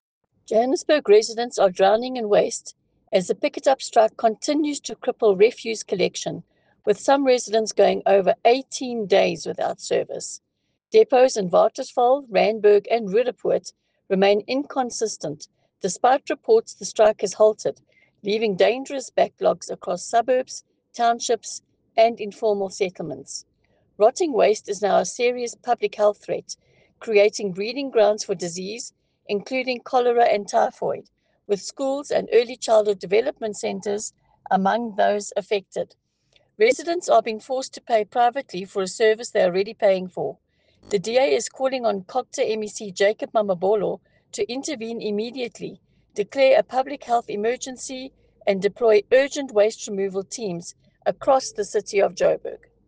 Issued by Leanne De Jager MPL – DA Gauteng Spokesperson for Environment
Afrikaans soundbites by Leanne De Jager MPL.